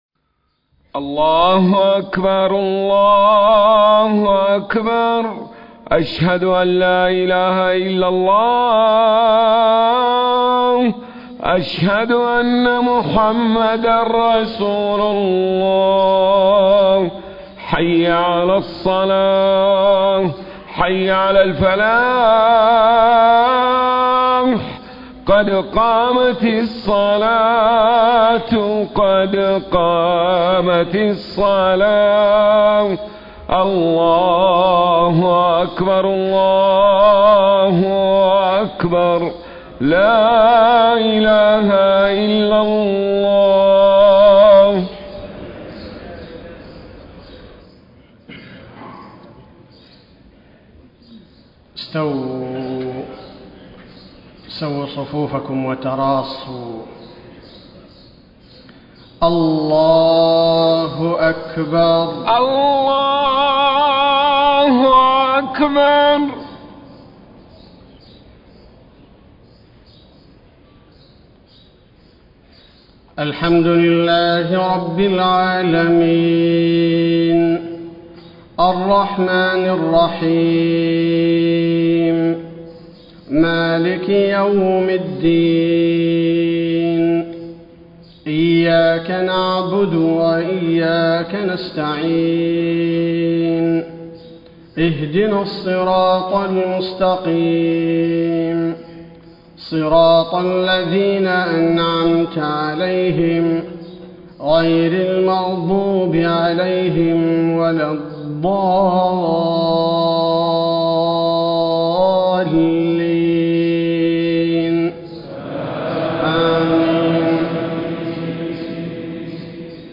صلاة الفجر 8 - 4 - 1434هـ من سورة هود > 1434 🕌 > الفروض - تلاوات الحرمين